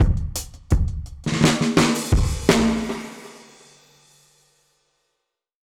Index of /musicradar/dub-drums-samples/85bpm
Db_DrumsB_Wet_85-04.wav